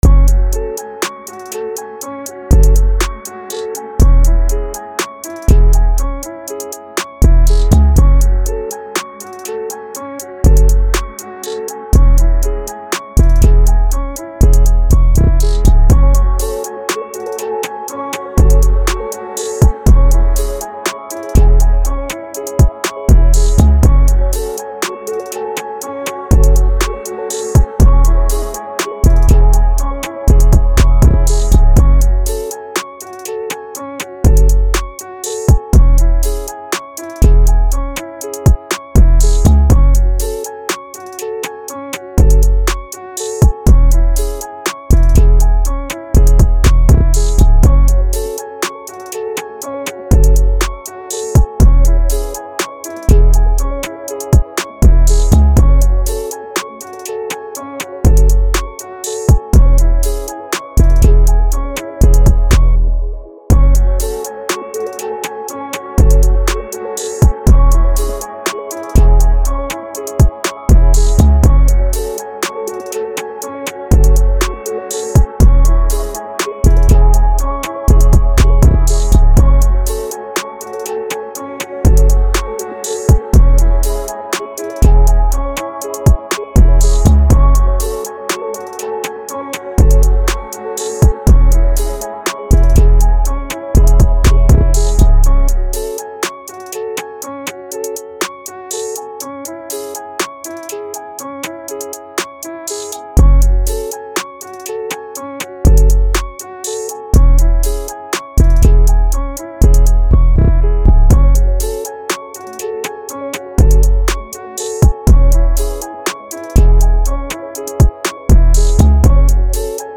Rap
c#Minor